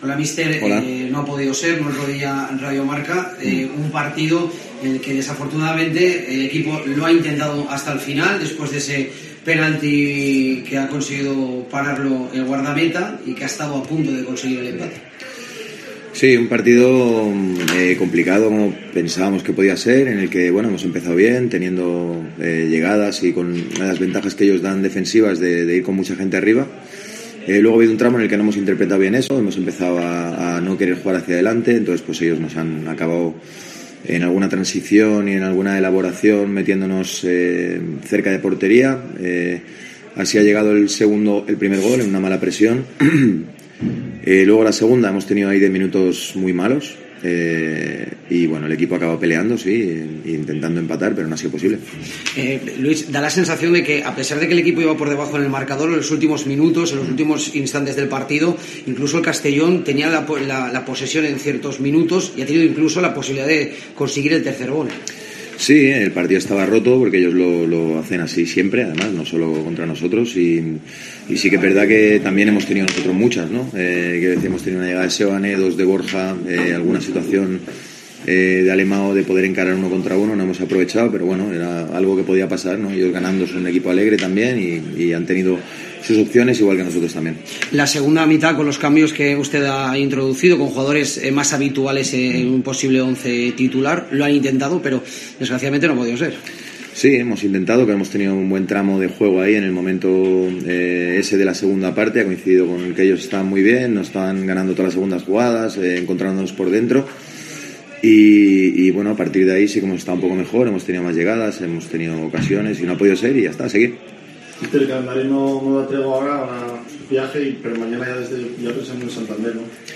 RUEDA DE PRENSA
Luis Carrión, entrenador del Real Oviedo, compareció en sala de prensa ante los medios de comunicación después de la eliminación de su equipo de la Copa del Rey a manos del CD Castellón.